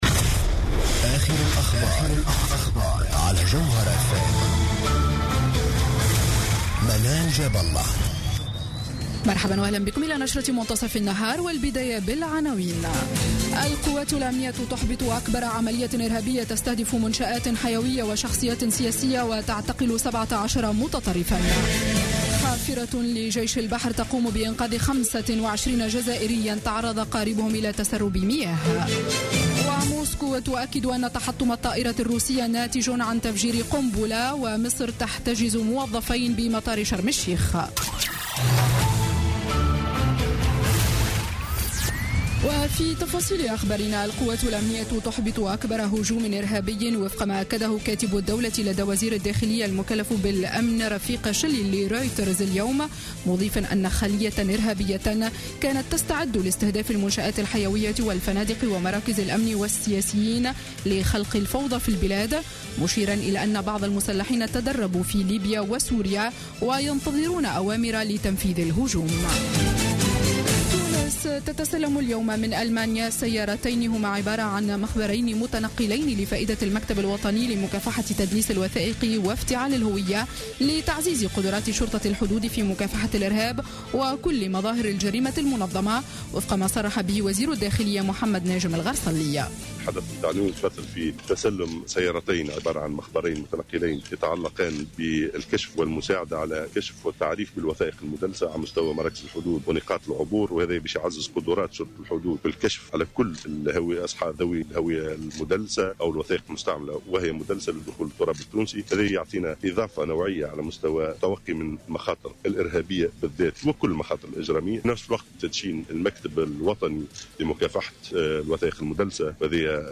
نشرة أخبار منتصف النهار ليوم الثلاثاء 17 نوفمبر 2015